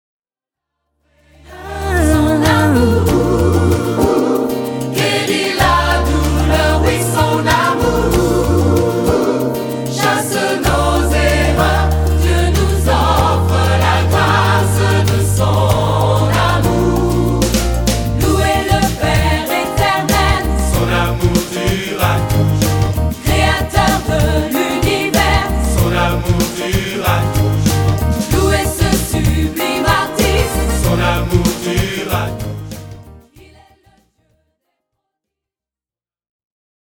Chants de louange originaux interprétés par leurs auteurs